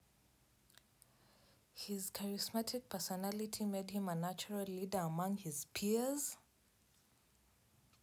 UniqueData/speech-emotion-recognition-dataset at ed7e3915a695f292aa3d621c60f1f68bc3b9d2d1
surprised.wav